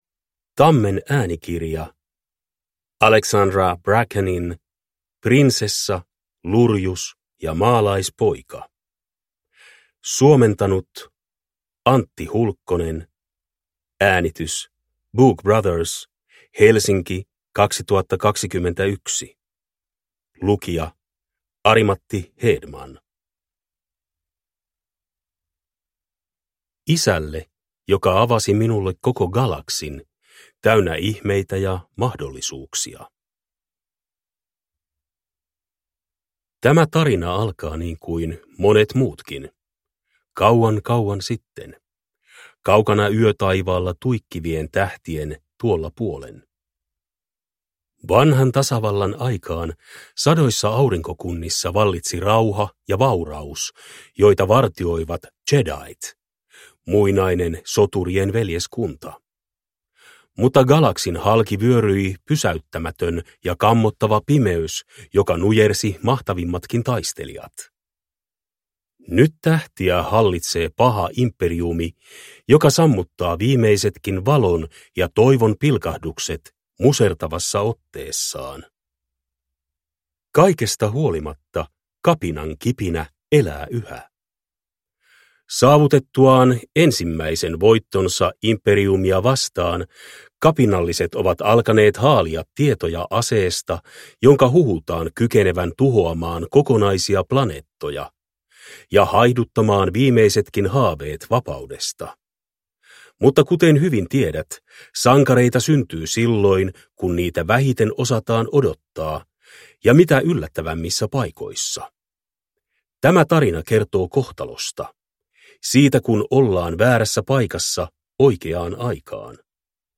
Prinsessa, lurjus ja maalaispoika (ljudbok) av Alexandra Bracken